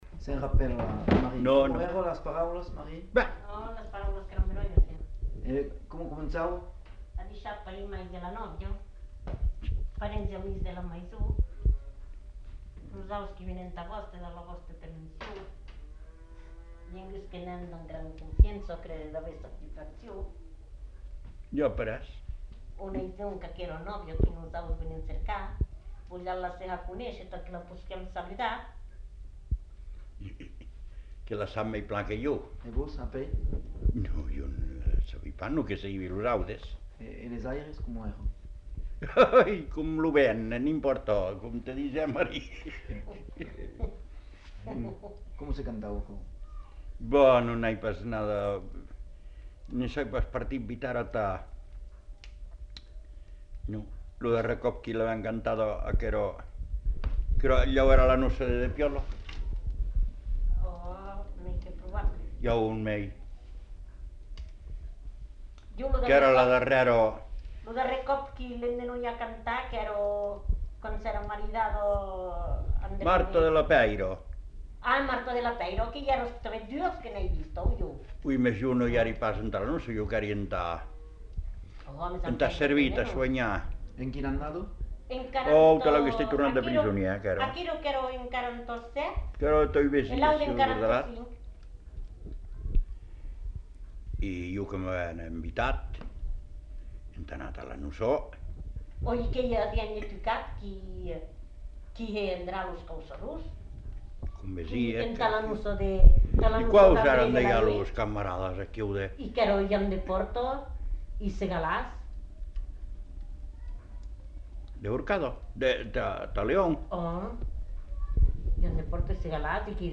Aire culturelle : Béarn
Lieu : Buzy
Genre : chant
Effectif : 1
Type de voix : voix de femme
Production du son : récité
Notes consultables : Chant suivi de quelques commentaires.